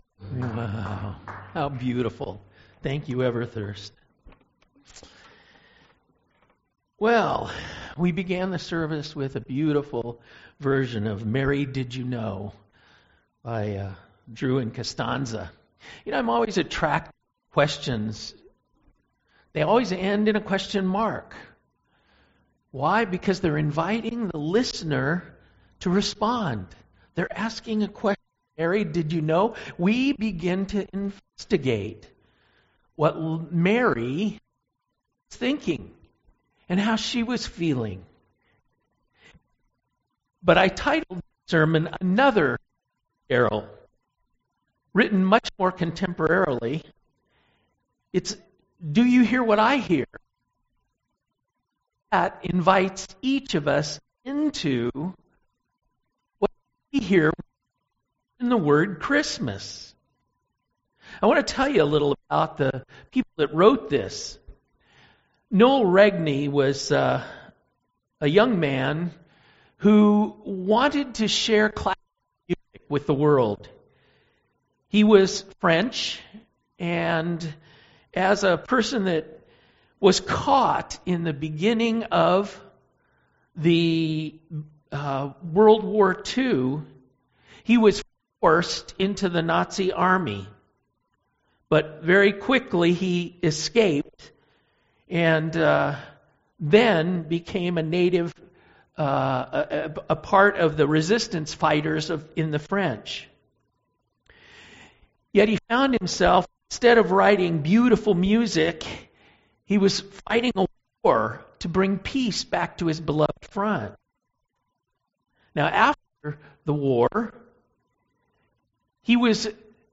Sermon Audio Archives | Church of Newhall